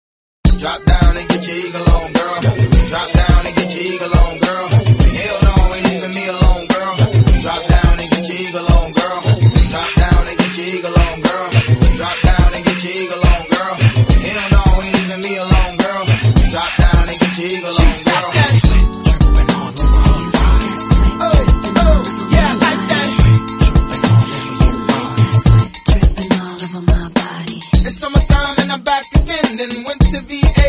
• Category Hip Hop